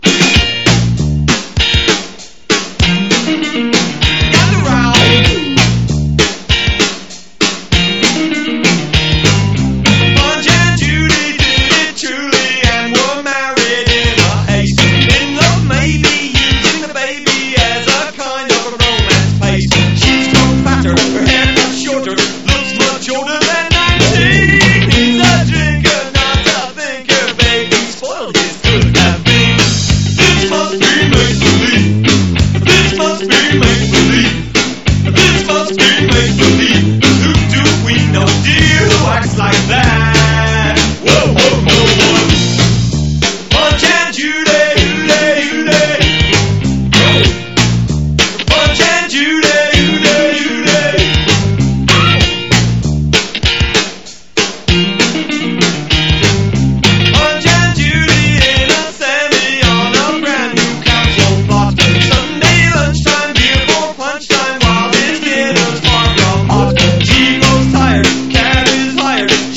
現在の耳で聴いてもかっこいいシンセ・ポップ/エレ・ポップの宝庫！
ヴォーカルが削られたミニマルなエレクトロ・トラックは、ハウス、ニューウェイヴ・ダブとしても評価したいところです！